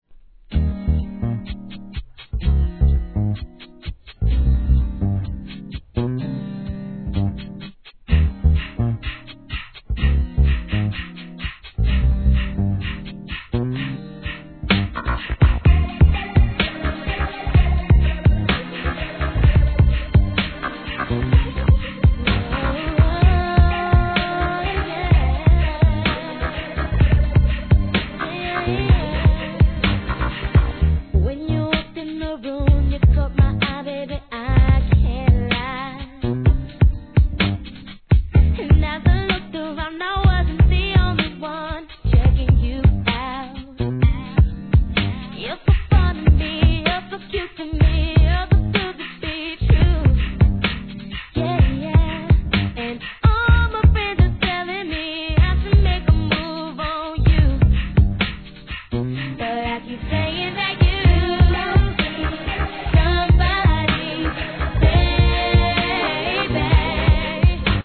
HIP HOP/R&B
可愛らしい歌声で聴かせるSLOW JAM♪